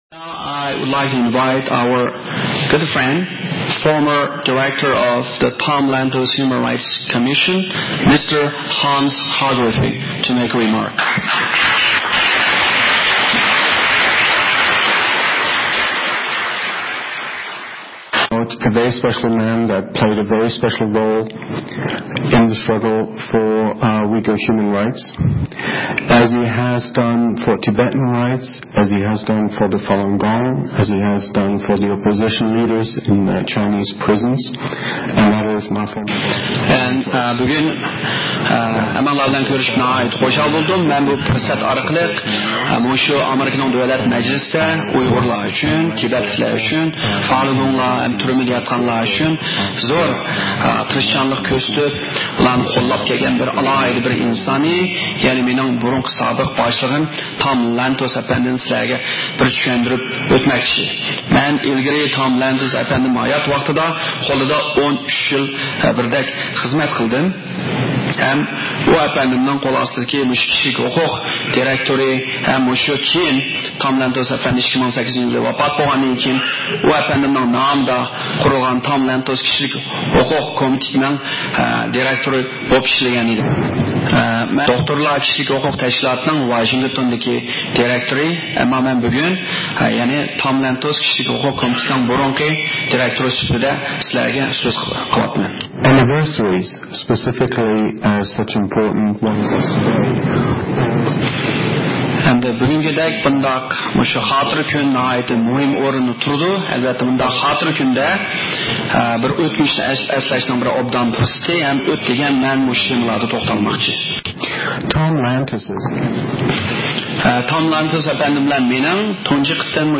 نەق مەيداندا تەرجىمە قىلىنىپ